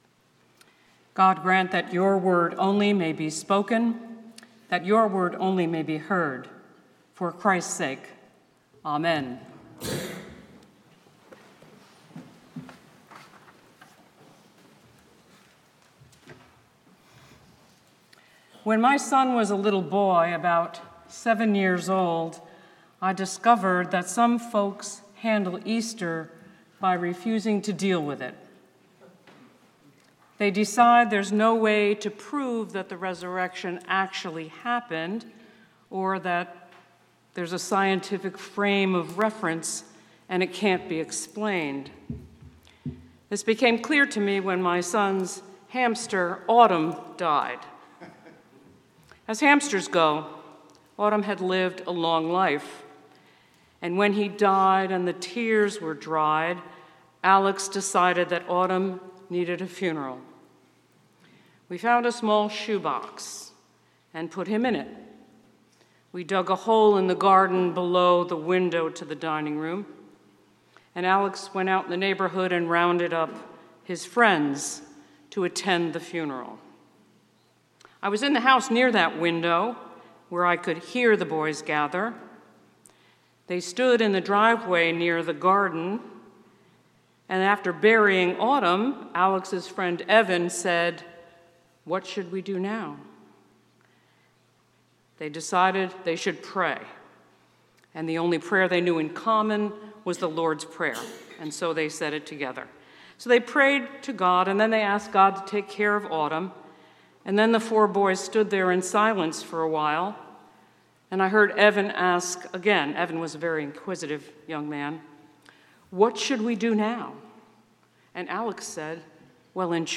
St-Pauls-HEII-8p-Homily-04APR26.mp3